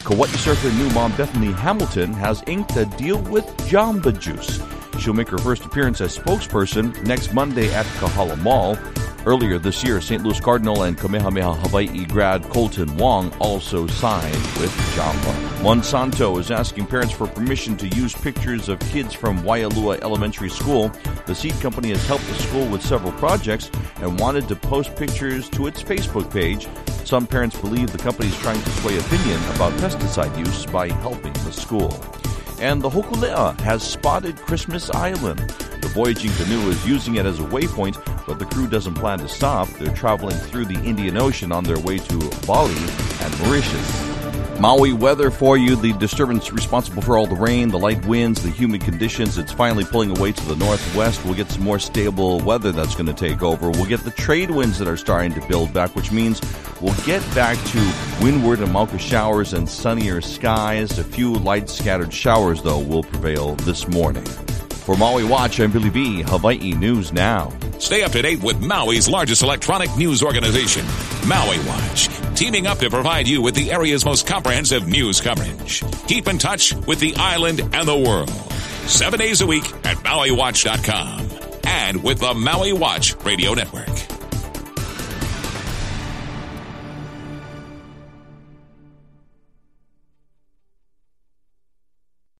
Your daily news & weather brief for August 18